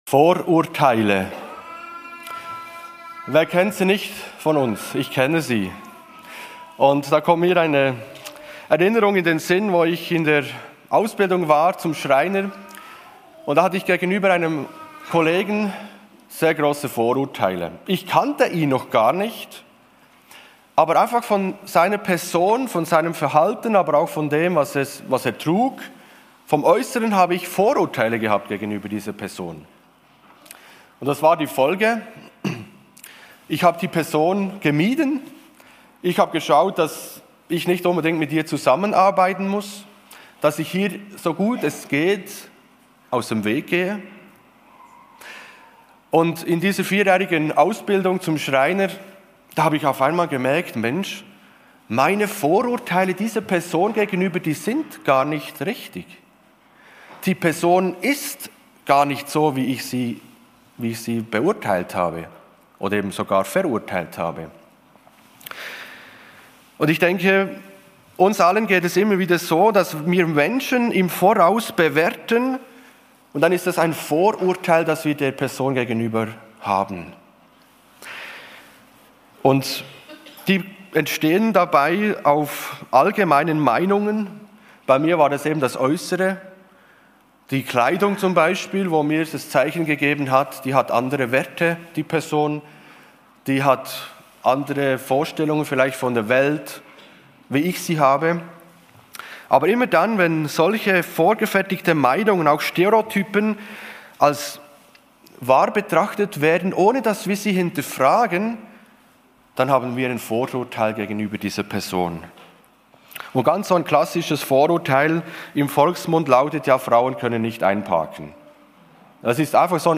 Deshalb wünschen wir dir, dass du mit diesen Predigten Gott erlebst.